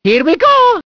One of Mario's voice clips in Mario Kart DS